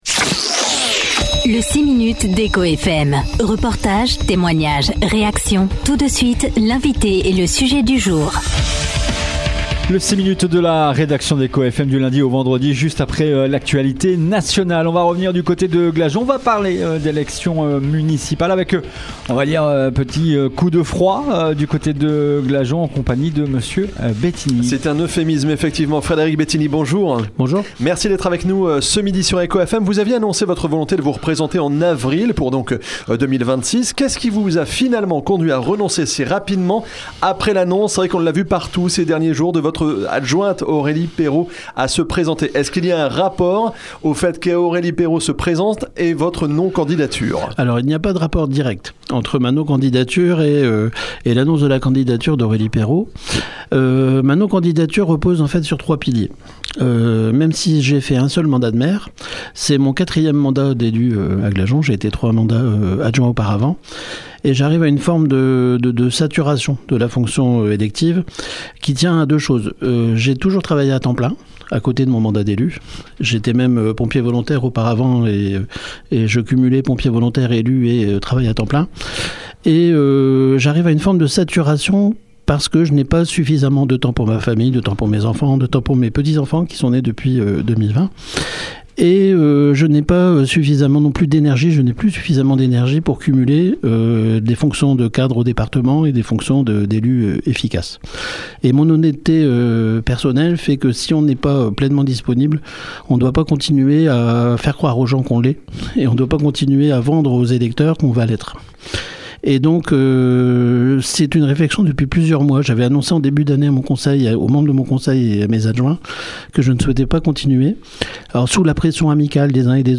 Ce vendredi 3 octobre, Frédéric Bettignies, maire de Glageon, était l’invité du 6 minutes Echo FM.
Une interview exceptionnelle de seize minutes, au cours de laquelle l’élu est revenu sur sa décision de ne pas briguer un nouveau mandat.